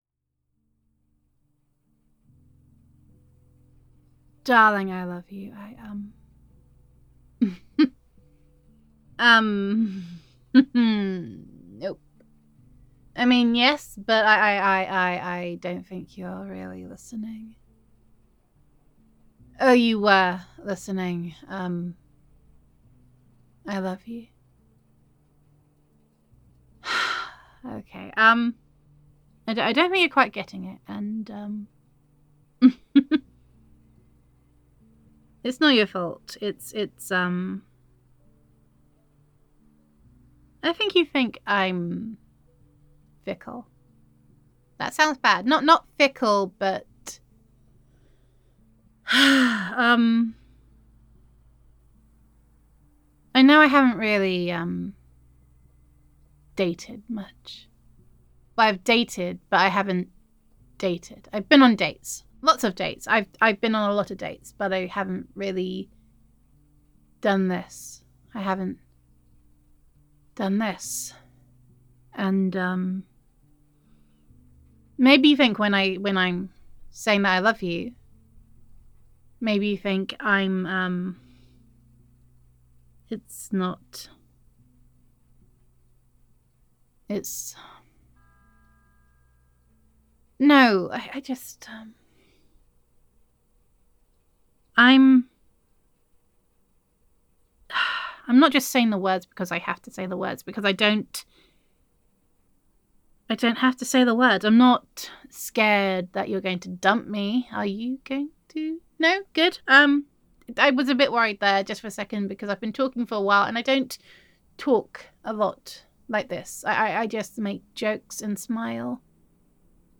[F4A] Just Let Me Love You [Girlfriend Roleplay][You Make Me Smile][Adoration][Being Myself][Falling in Love][Gender Neutral][All Your Girlfriend Wants to Do Is Just Love You]